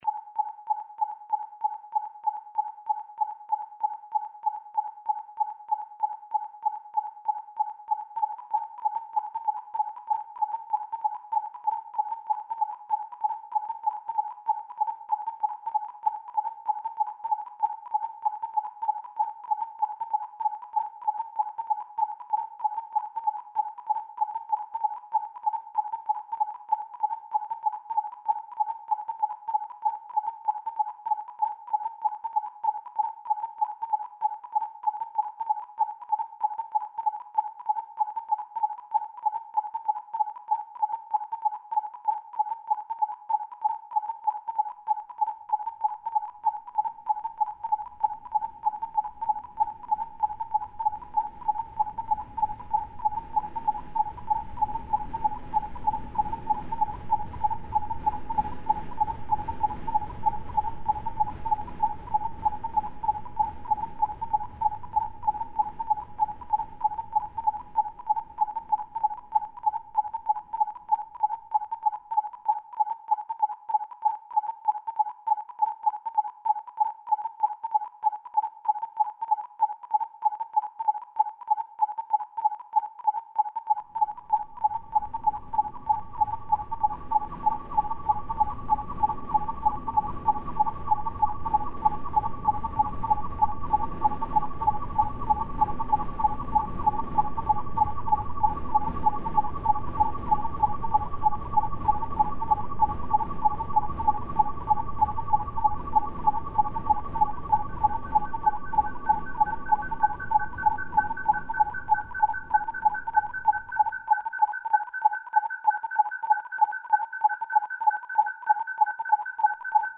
These were then scanned and the noises made by the scanner preserved as audio files, which the composer manipulated in the computer to build tracks.